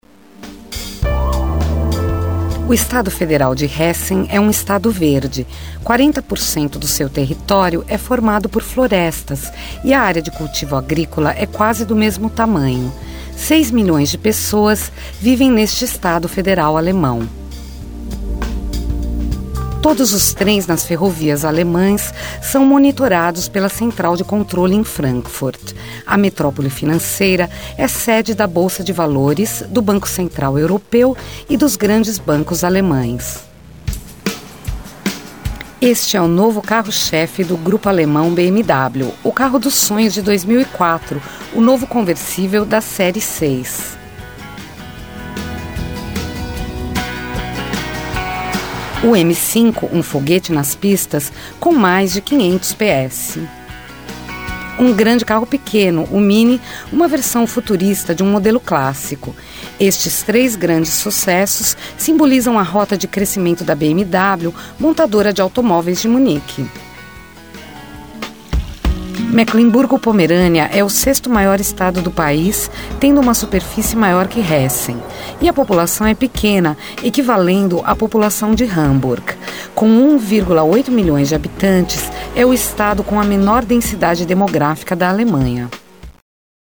Sprecherin für Portugiesisch (Muttersprache), Deutsch bzw. Italienisch (mit Akzent).
Nativespeaker (female)for Portuguese, German and English